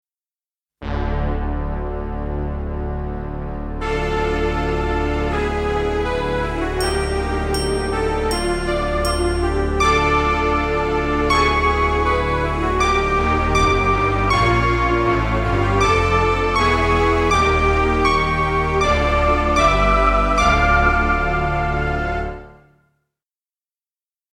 Extra soundmixbegeleiding voor tutors instrument: